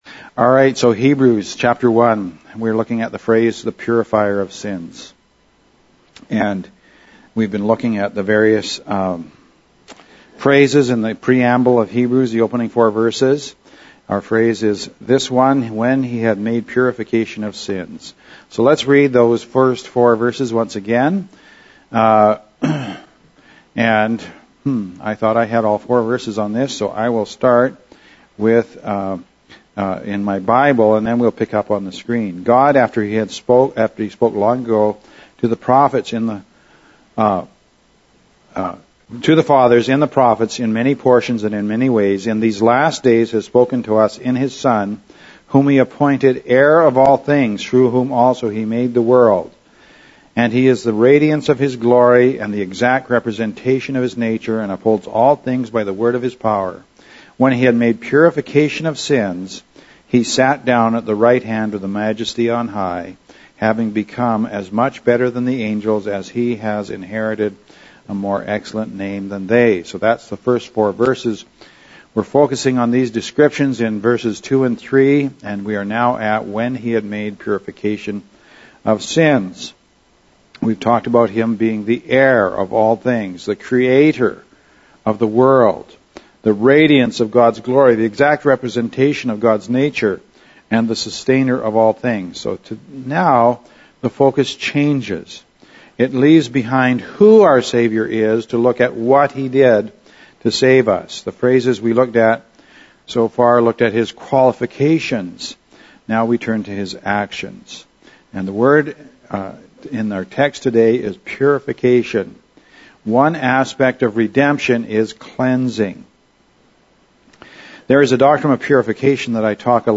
Hebrews 1.3 – As we continue to focus on the person of Christ from the opening verses of Hebrews for our communion service, we consider what it means that he purified sins.